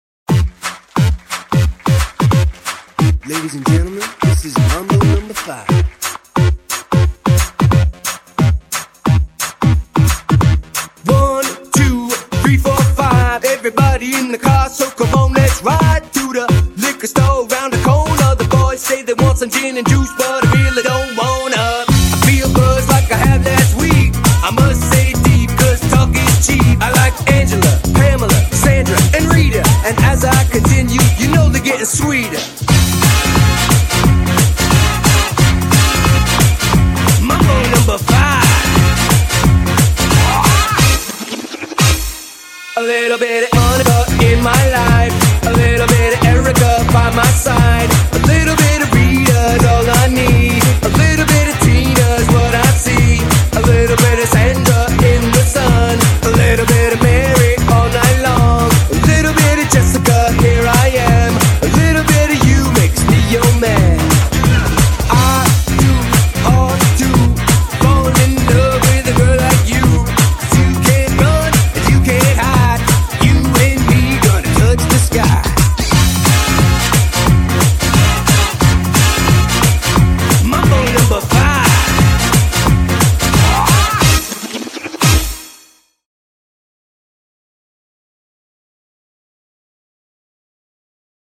BPM140--1
Audio QualityPerfect (High Quality)